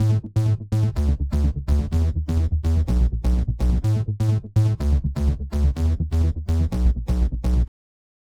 80s Forever Bass.wav